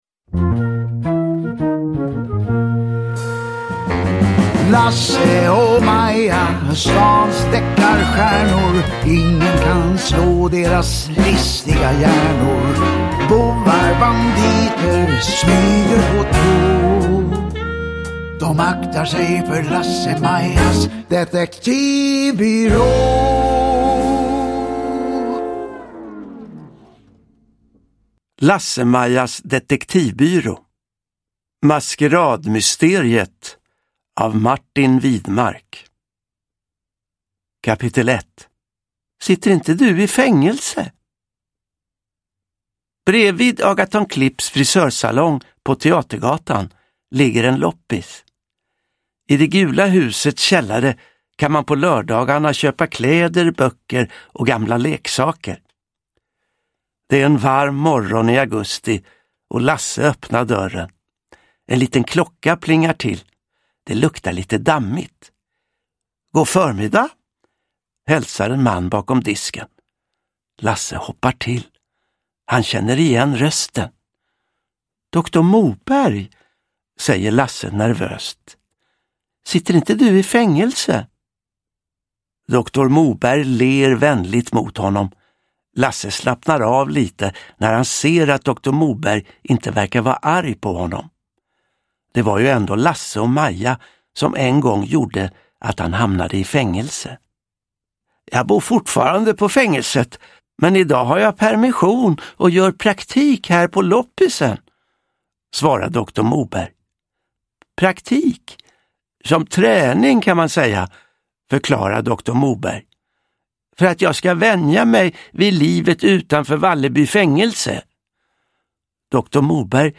Uppläsare: Johan Ulveson